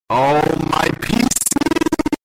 Oh My Pc Sound Effect sound effects free download